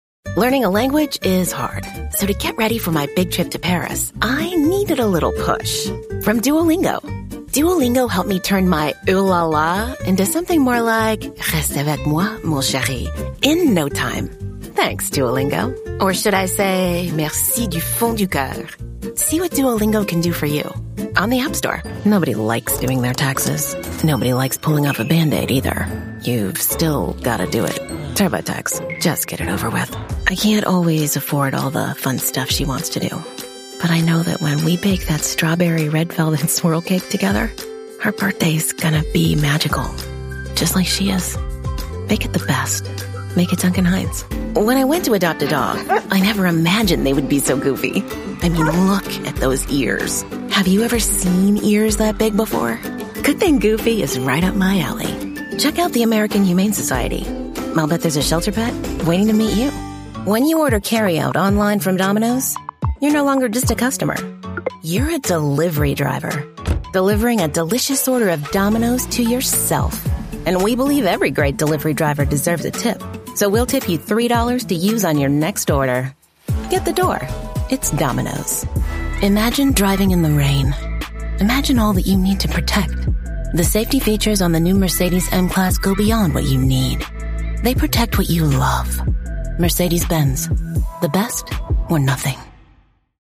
Commercial Demo
English (American)
Young Adult
Middle-Aged
Mezzo-Soprano
ConversationalSultryDarkBrightTrustworthyAuthoritativeFriendlyAccurateCharming